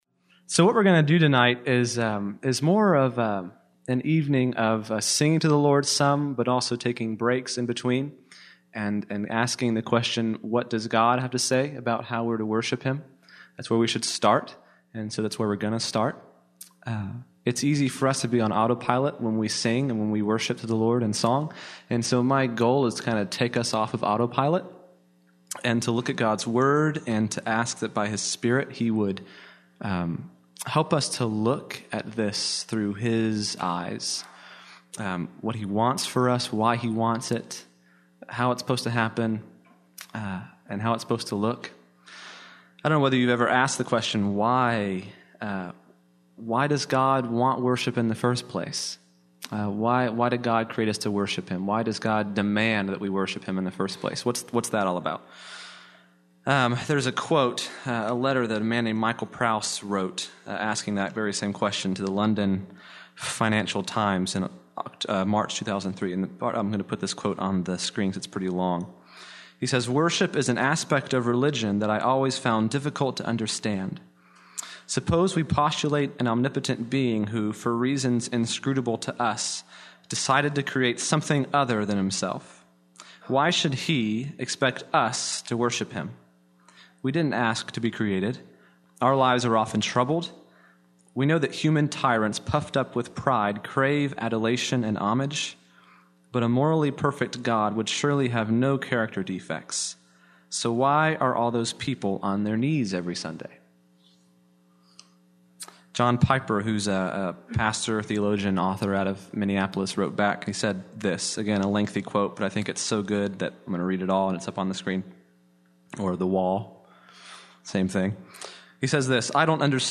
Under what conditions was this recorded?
The second was my church’s newly re-launched men’s ministry, about 75 guys who get together once a month. My goal was to intersperse teaching and singing over the course of an hour, helping to lay a biblical foundation for why we worship God, and how that looks.